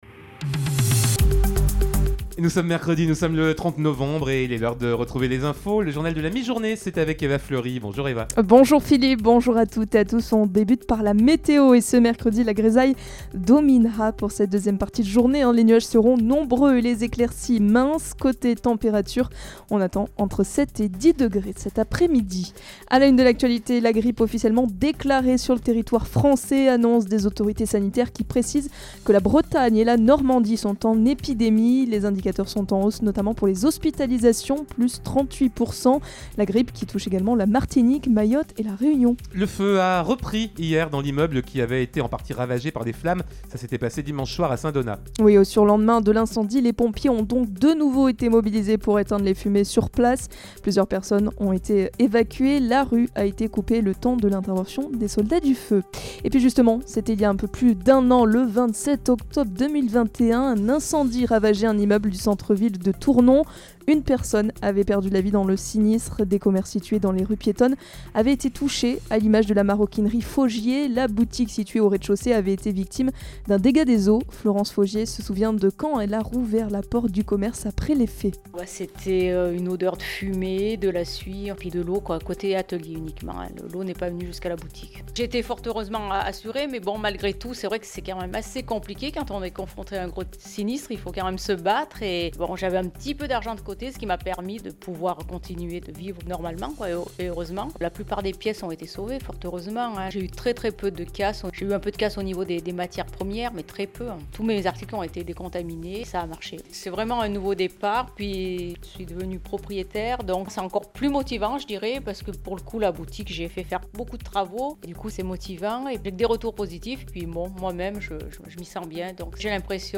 in Journal du Jour - Flash
Mercredi 30 novembre: Le journal de 12h